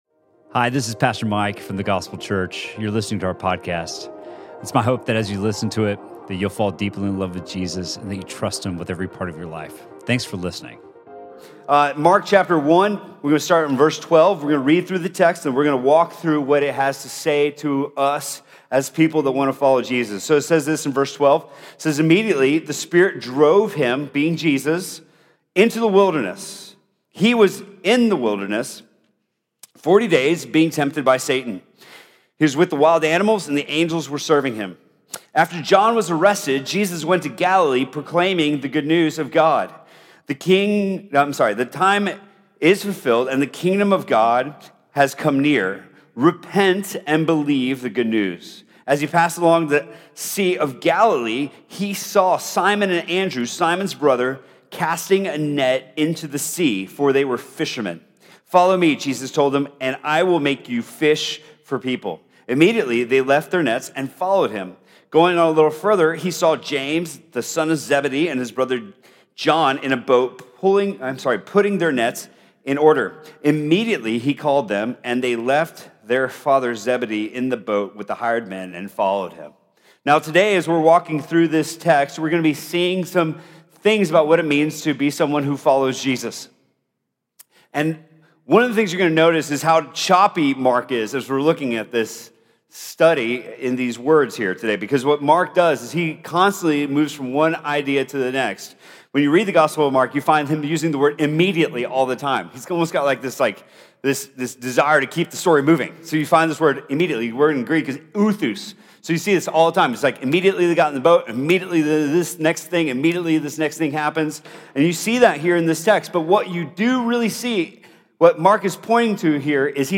Sermon from The Gospel Church on February 10th, 2019.